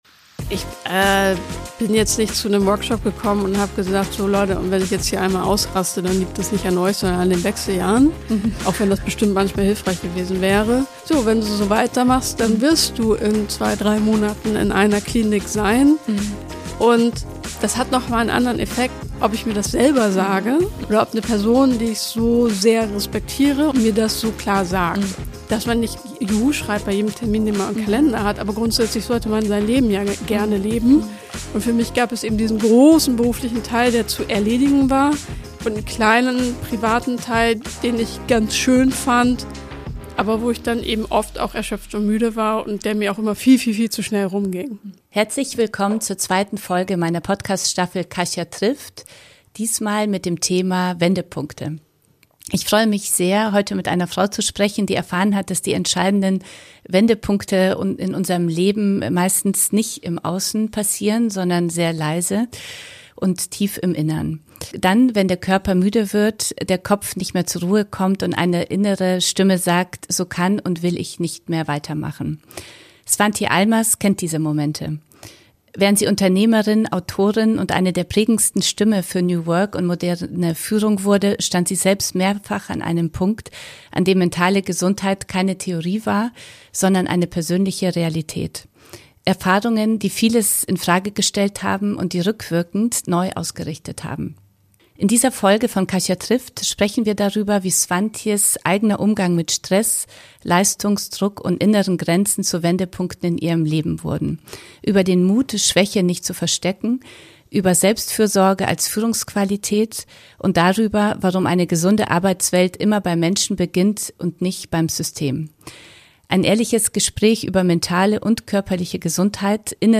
Ein tiefes Gespräch über Leistungsdruck, Identität, mentale Gesundheit und den Mut, sich selbst wichtiger zu nehmen.